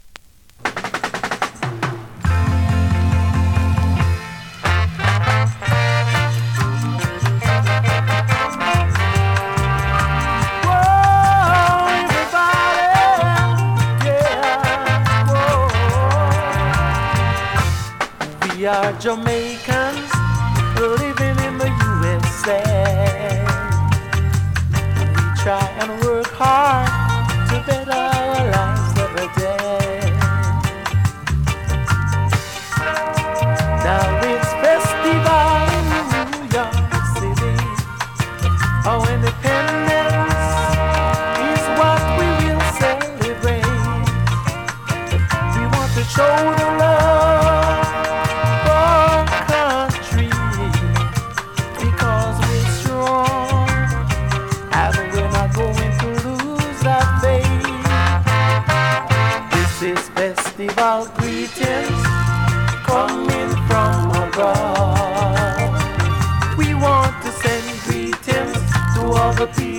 2025!! NEW IN!SKA〜REGGAE
スリキズ、ノイズ比較的少なめで